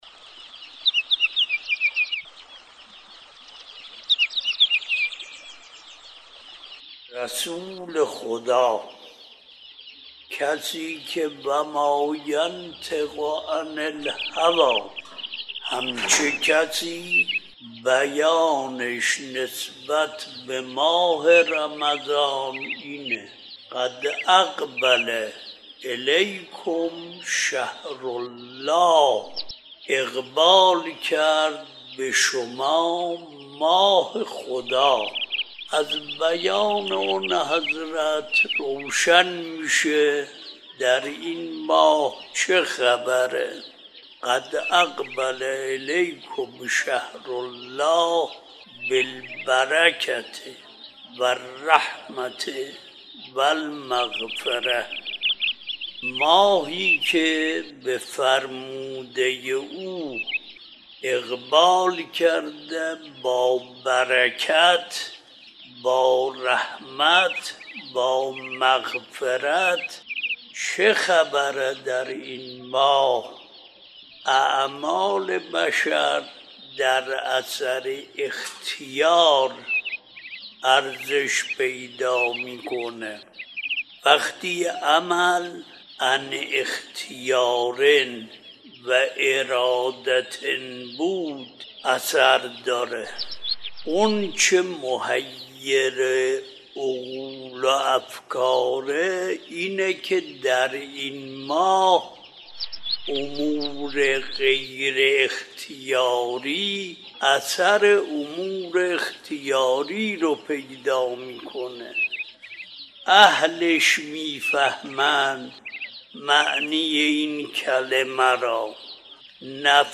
مجموعه پادکست «روایت بندگی» با کلام اساتید بنام اخلاق به کوشش ایکنا گردآوری و تهیه شده است که چهل‌وچهارمین قسمت این مجموعه با کلام آیت‌الله وحید خراسانی با عنوان «فضیلت ماه مبارک رمضان» تقدیم مخاطبان گرامی ایکنا می‌شود.